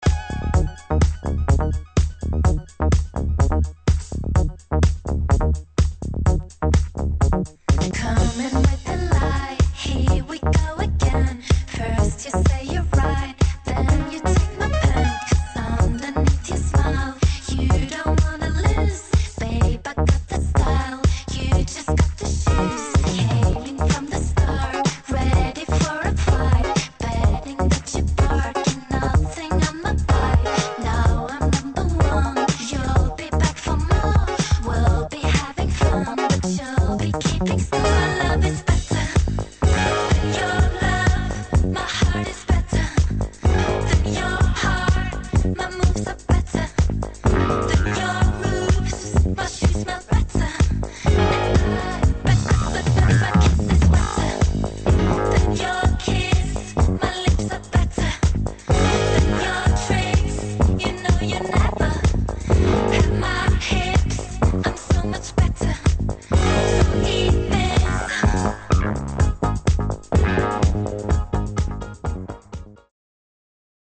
[ ELECTRO POP / DUBSTEP ]